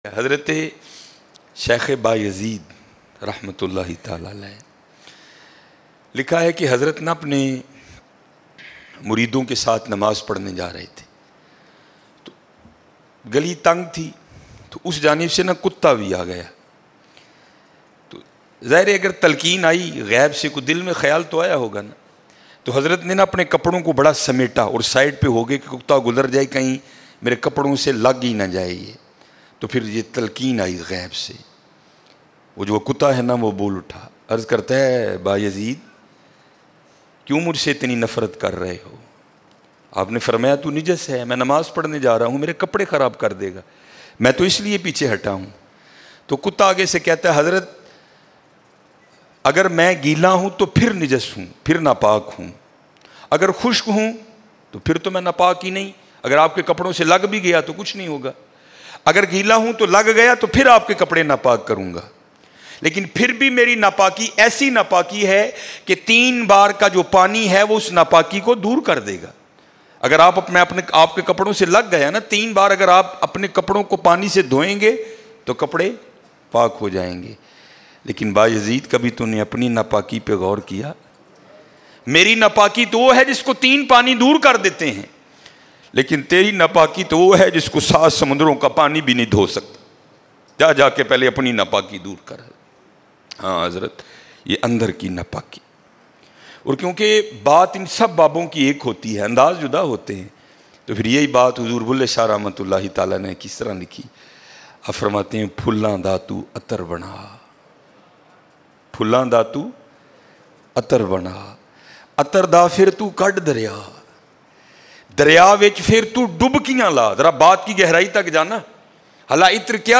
Allah Apny Bandon sy Kitna Pyar kerta Hai Bayan lattest